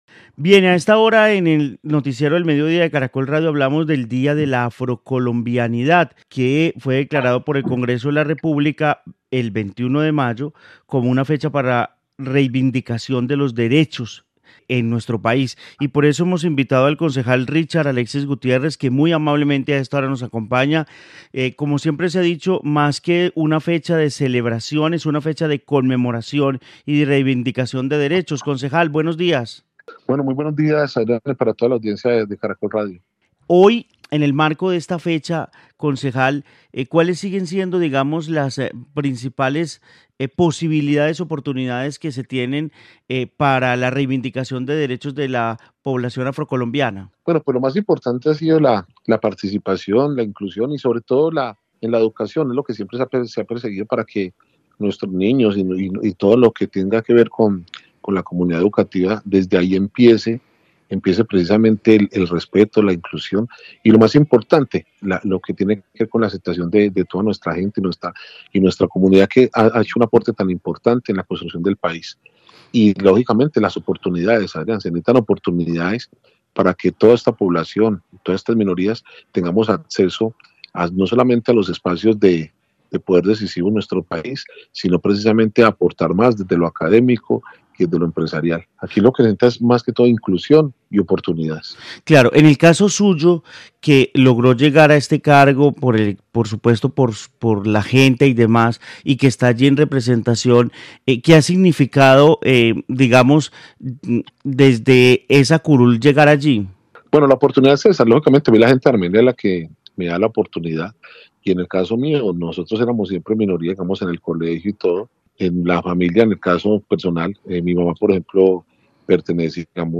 El concejal de Armenia Richard Alexis Gutiérrez y las reflexiones sobre afrocolombianidad
En el noticiero del mediodía de Caracol Radio hablamos del día de la Afrocolombianidad que fue declarado por el Congreso de la República el 21 de mayo como una fecha para reivindicación de los derechos en nuestro país y por eso hemos invitado al concejal de Armenia Richard Alexis Gutiérrez sobre esta población en la ciudad.